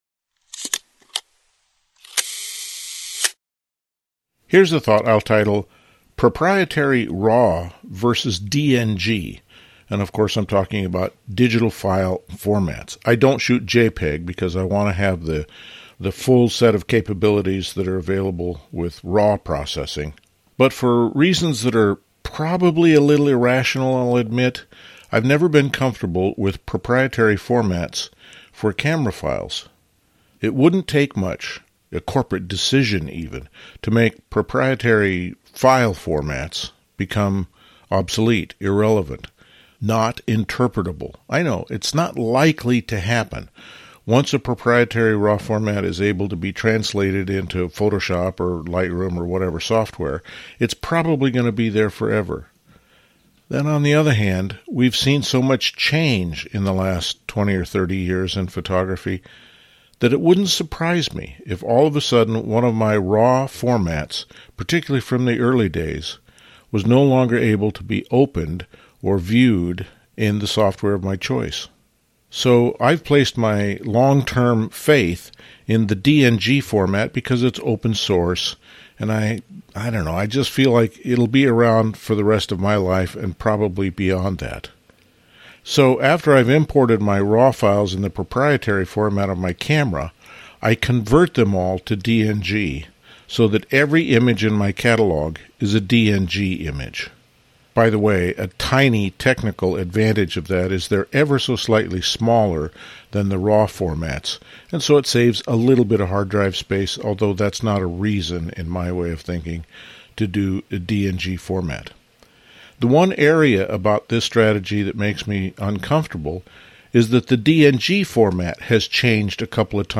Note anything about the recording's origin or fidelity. Included in this RSS Feed are the LensWork Podcasts — posted weekly, typically 10-20 minutes exploring a topic a bit more deeply — and our almost daily Here's a thought… audios (extracted from the videos.)